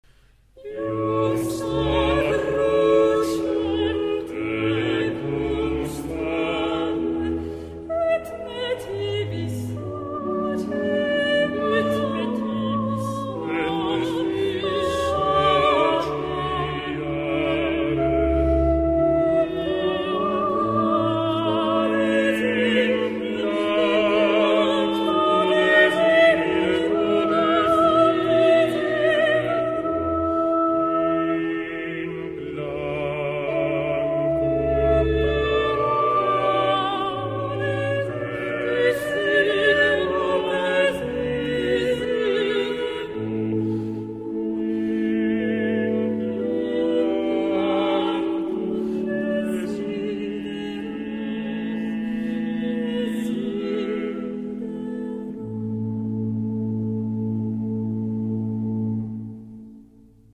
Coro della radio Svizzera - Diego Fasolis & Aura Musicale Budapest - René Clemencic
ATTENTION ! Dans cet enregistrement, on utilise le "diapason baroque" (La415).
On l'entend donc environ un demi-ton en dessous du diapason actuel (La440) que l'on emploiera et qui est celui des fichiers midi et virtual voice.